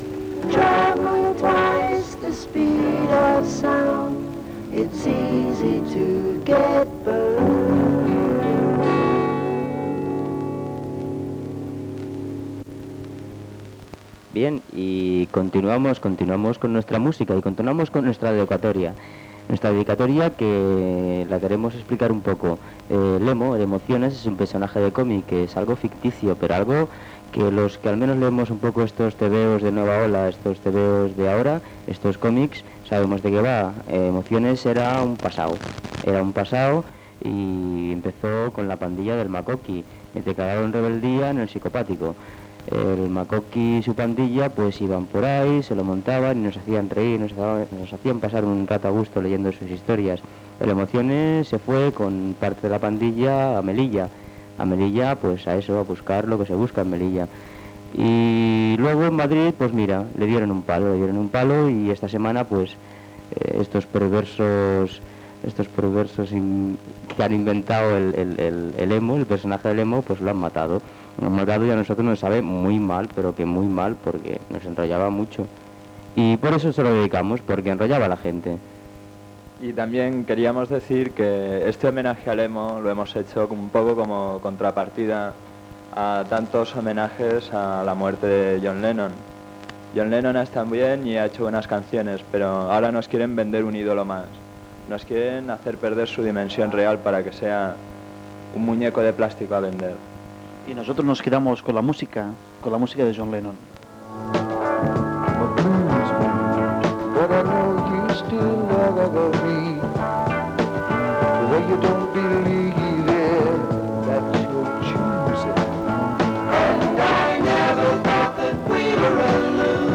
Comentari sobre la mort del personatge de còmic Lemo i de la mort del músic John Lennon, música, identificació i comiat de l'emissió.
FM